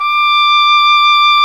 Index of /90_sSampleCDs/Roland LCDP04 Orchestral Winds/CMB_Wind Sects 1/CMB_Wind Sect 2
WND OBOE3 D6.wav